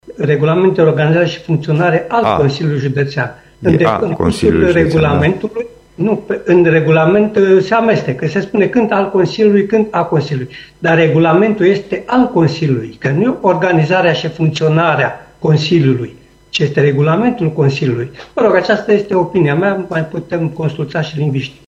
Sedinta de plen a Consiliului Judetean Timis
Pentru că în document apărea atât regulamentul de funcționare și organizare AL Consiliului Județean Timiș, cât și A Consiliului Județean Timiș, consilierul Petru Andea a cerut corectarea erorilor.